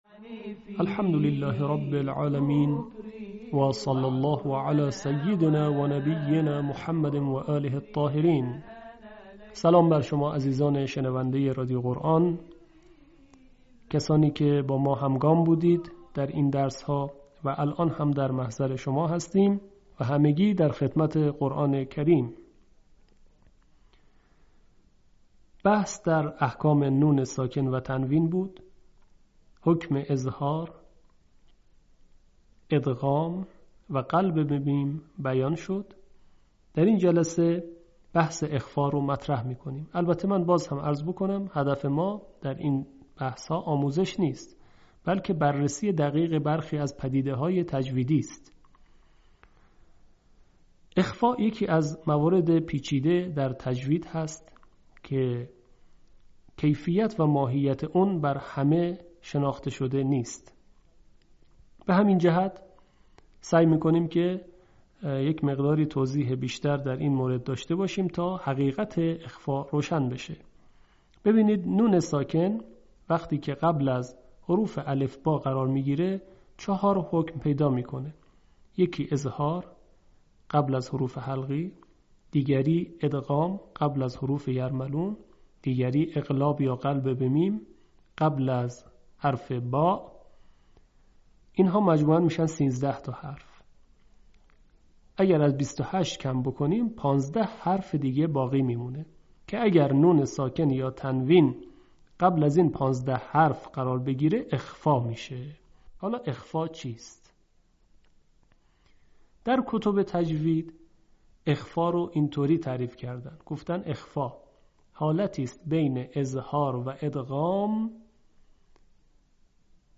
آموزش تجوید تحقیقی